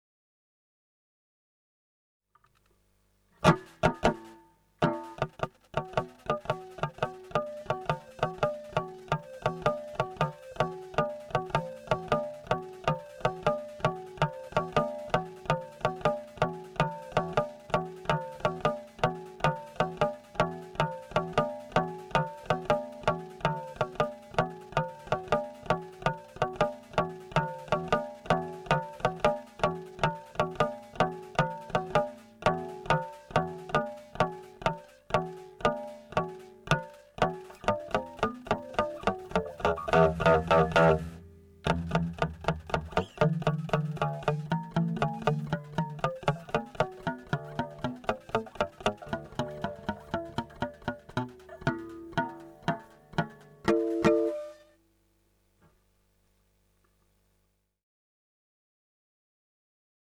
ノイズ誘導 のみだったか。